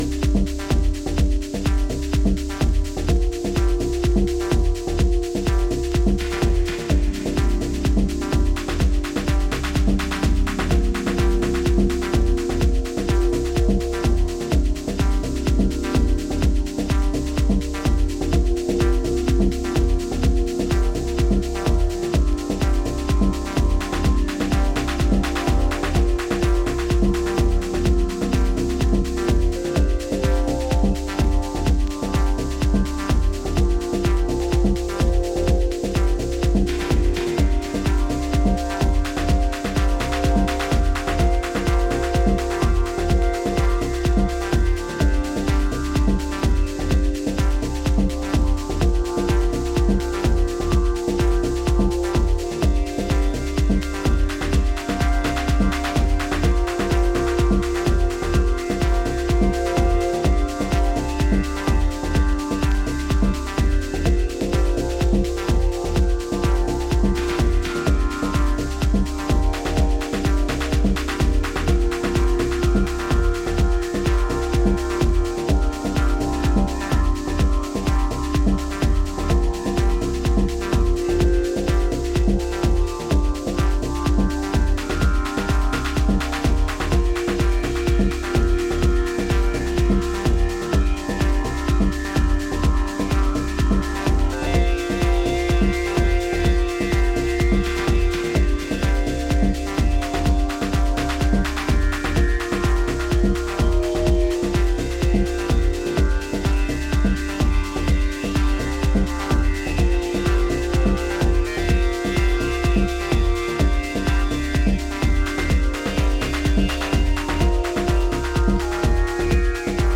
Afro Latin and deep house
seductive depths